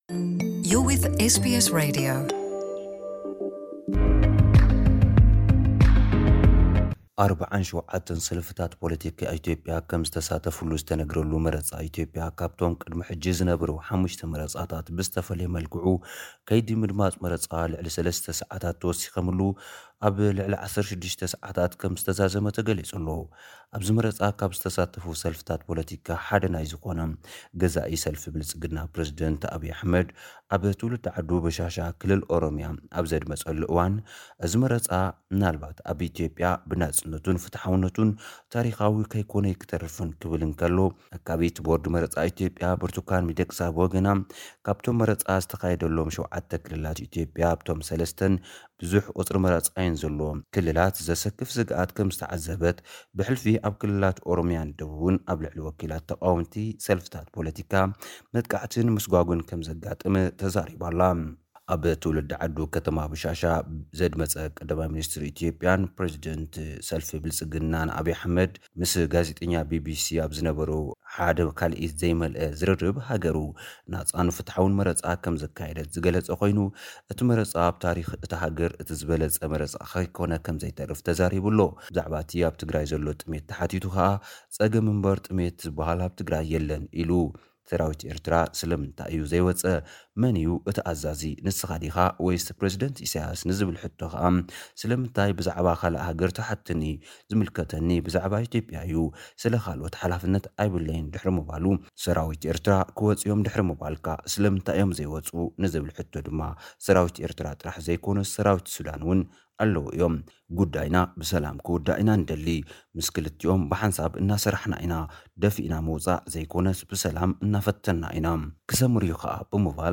ኣብ ኢትዮጵያ ሻድሻይ ሃገራዊ ምርጫ ክካየድ ዊዑሉ ኣሎ፡ ናይዚ ዉዕሎ ጸብጻብ ኣብዚ ብድምጺ ቀሪቡ ኣሎ።